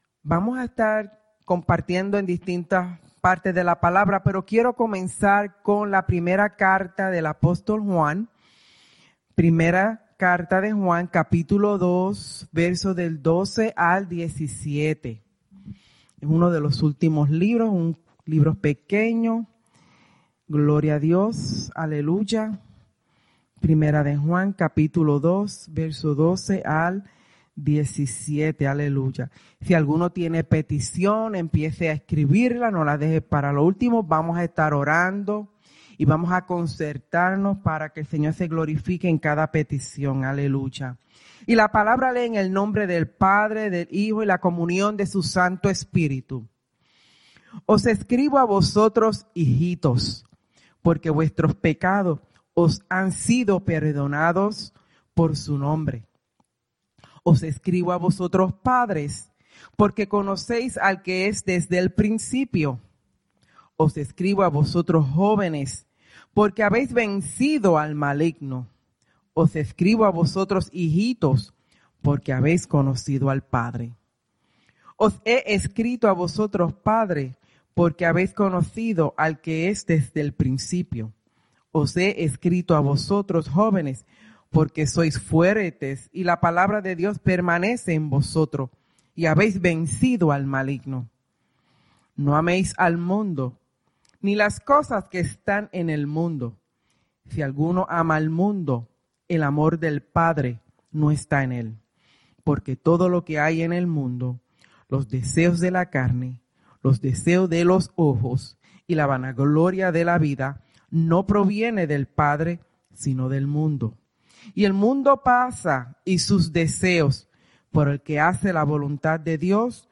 Souderton, PA